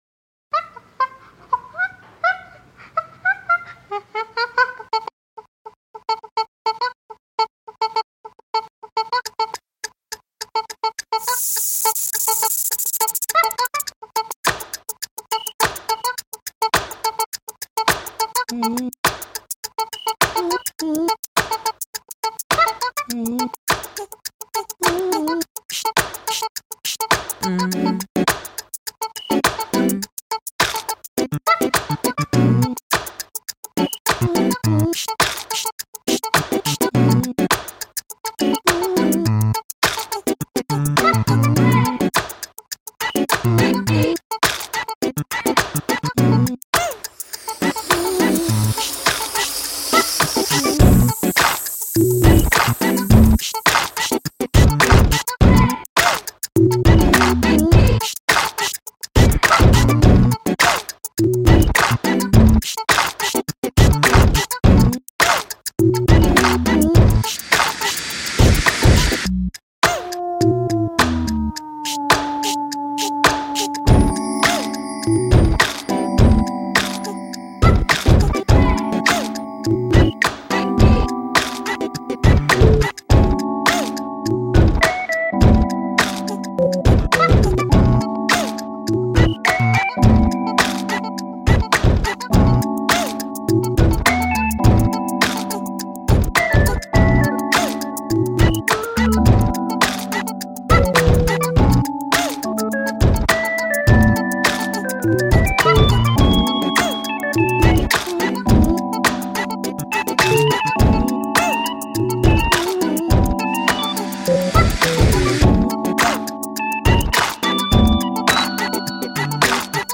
Wonderfullly creative use of manipulated wolf howls as organ sound. Great chimp loops as rhythm.  Creative the use of the balloon effects.